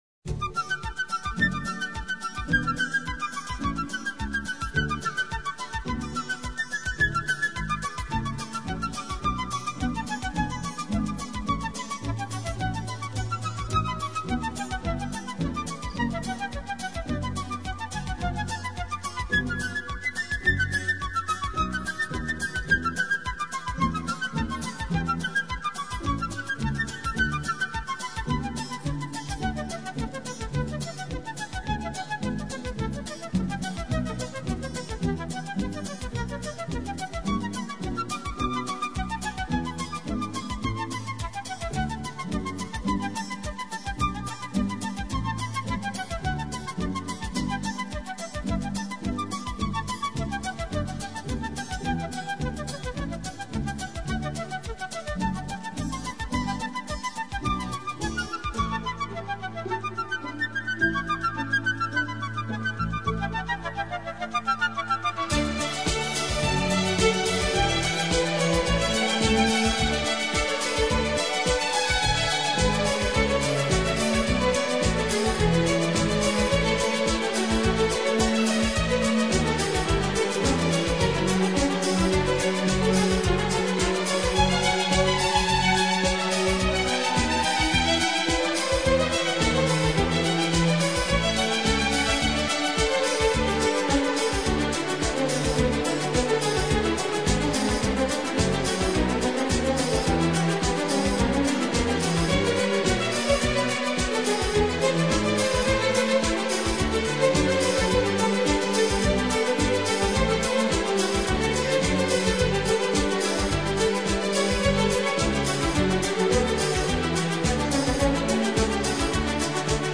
Genres:Classic